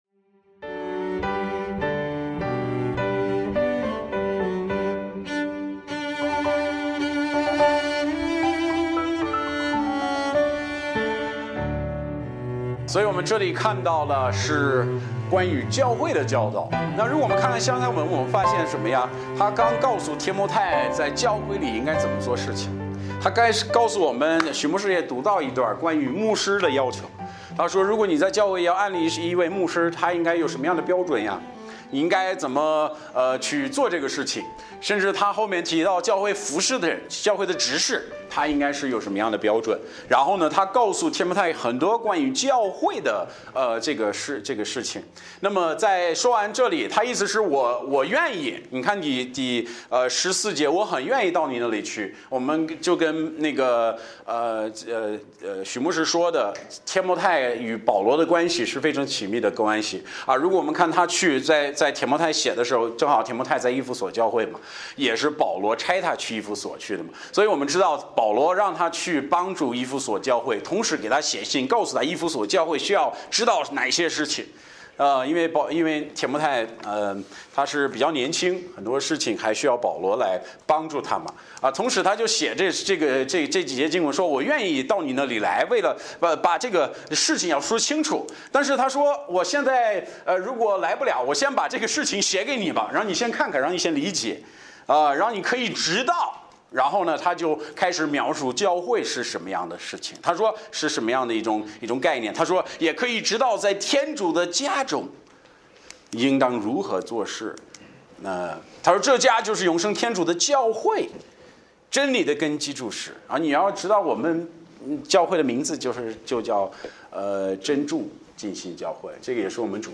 Bible Text: 提摩太前书 3:14-16 | 讲道者